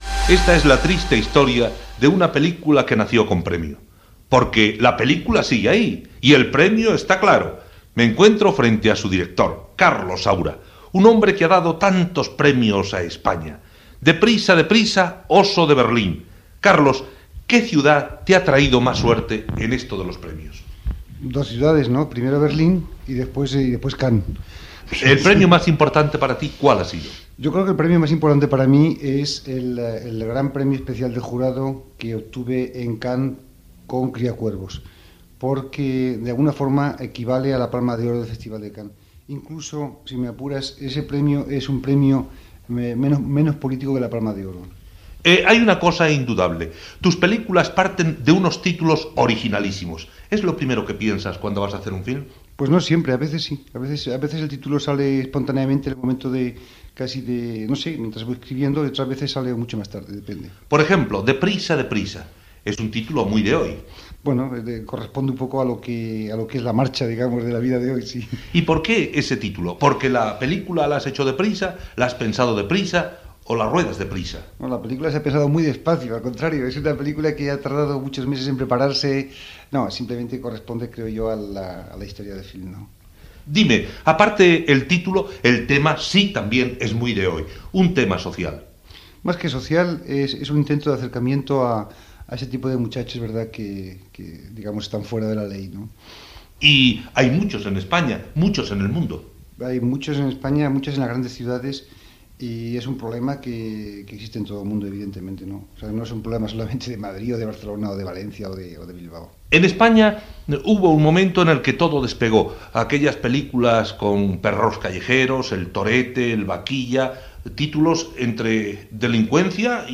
Entrevista al cineasta Carlos Saura. S'hi parla dels seus premis, dels títols de les seves pel·lícules, el gènere de le pel·lícules protagonitzades per delinqüents joves...